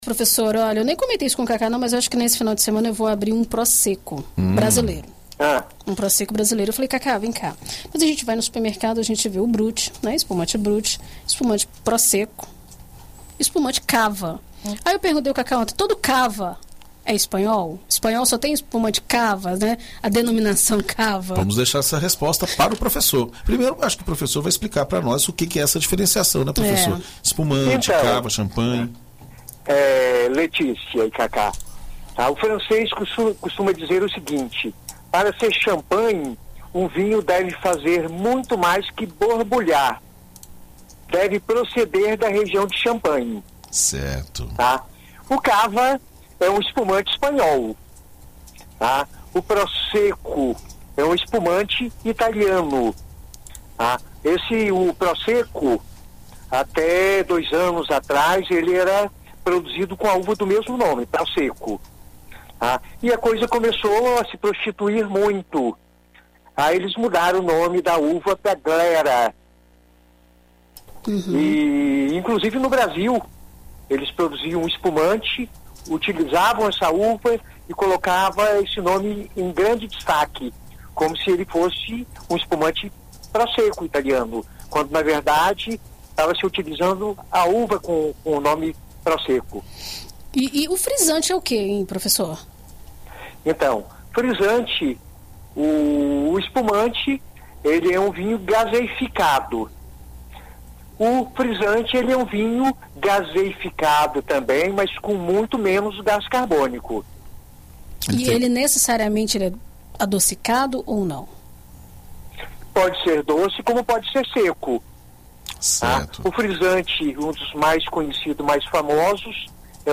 Enólogo explica os diferentes tipos de espumantes